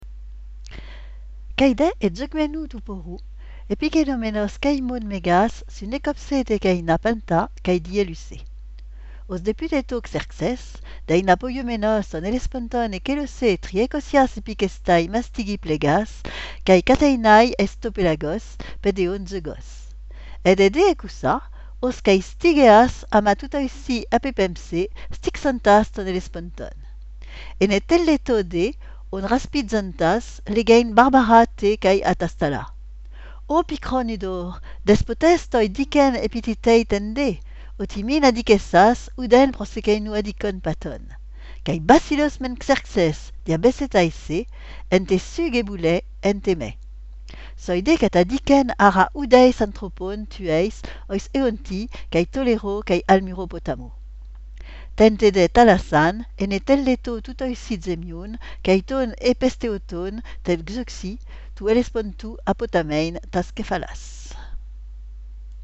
Hérodote, VII, 34-35 Votre navigateur ne prend pas en charge cette ressource, vous pouvez la télécharger ici : Écoutons la lecture de ce texte Écoutons la lecture de ce texte | Informations [ 2 ]